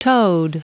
Prononciation du mot toad en anglais (fichier audio)
Prononciation du mot : toad